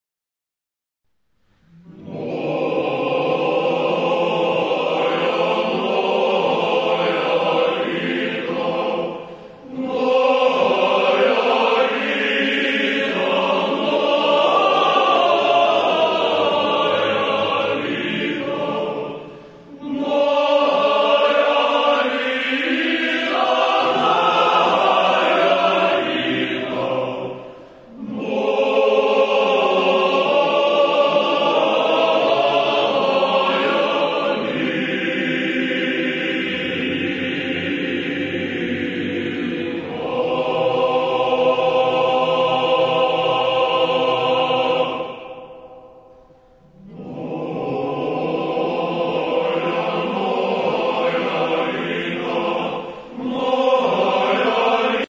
Народна (248)